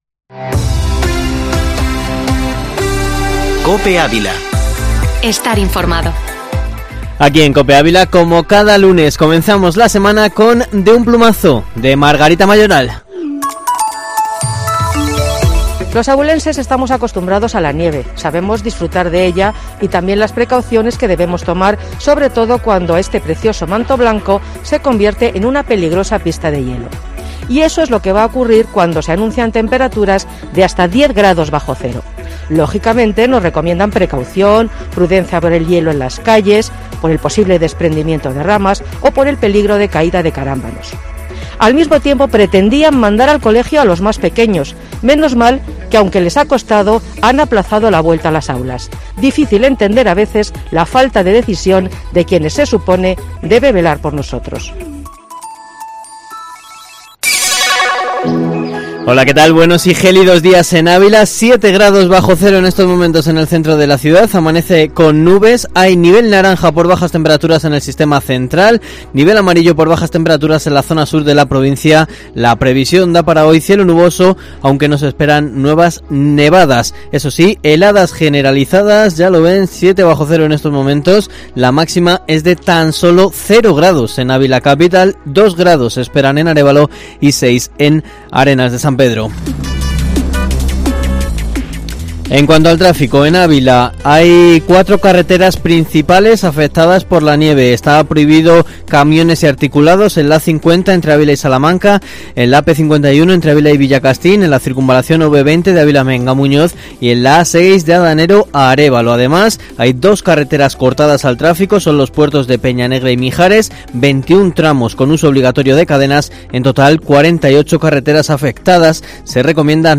Informativo matinal Herrera en COPE Ávila 11/01/2021